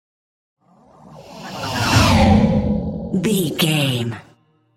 Whoosh monster breath
Sound Effects
Atonal
scary
ominous
eerie
whoosh